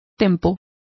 Also find out how tempo is pronounced correctly.